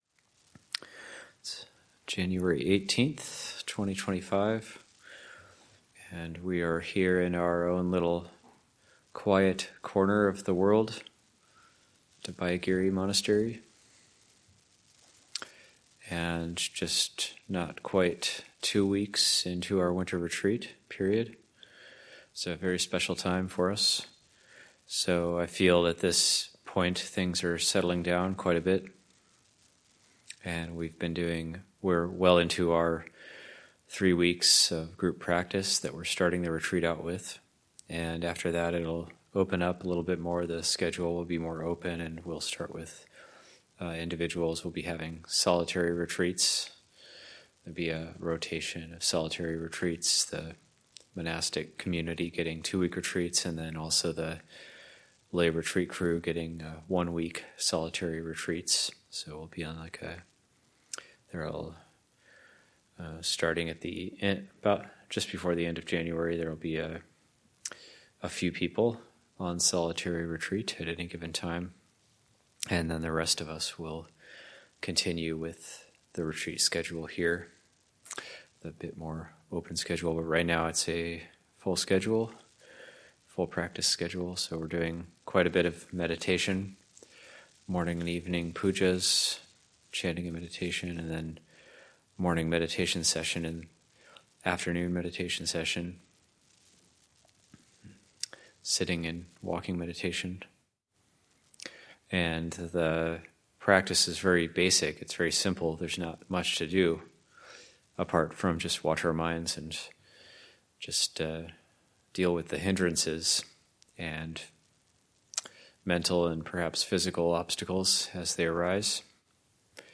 He gives teachings on the importance of maintaining sila, cultivating continuous mindfulness, exerting the correct amount of effort, and the proper way to live in harmony within a community of practitioners. This Dhamma talk was offered on January 6, 2025…